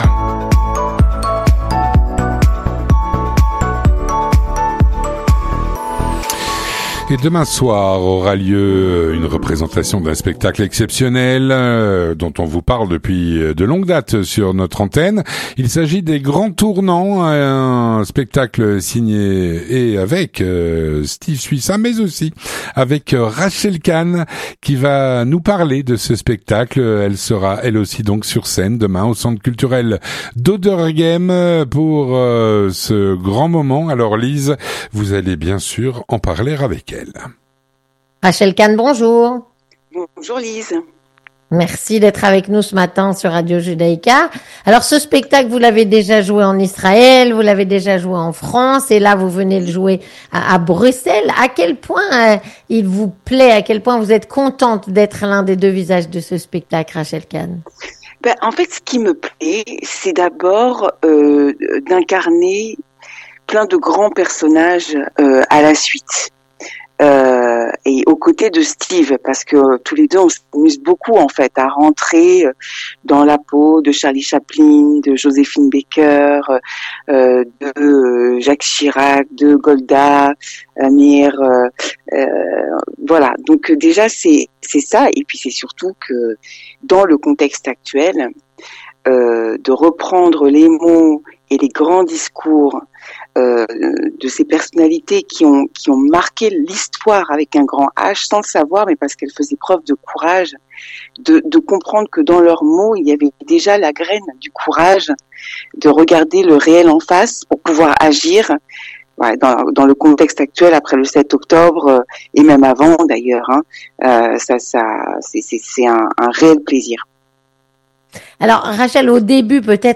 Rencontre - Rachel Khan nous parle “des grands tournants” qu’elle joue à Bruxelles demain soir.